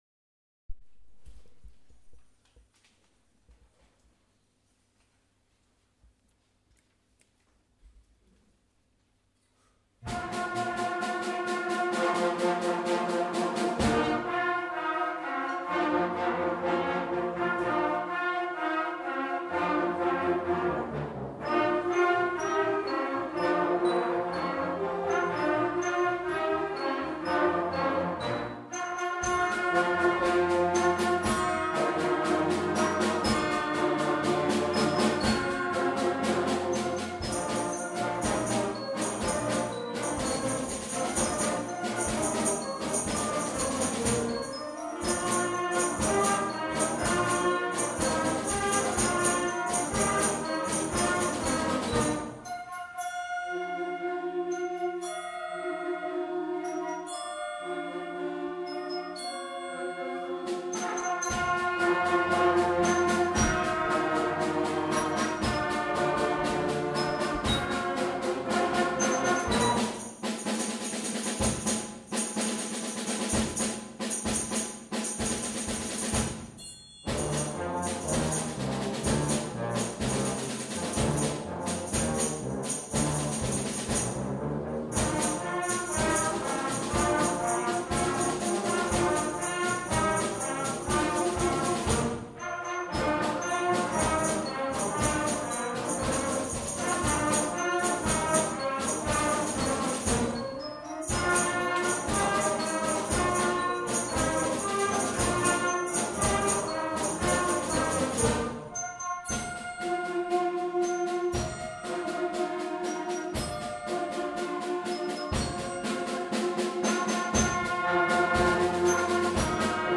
Junior Wind Band - Presto
A Concert of Wind, Brass and Percussion, April 2015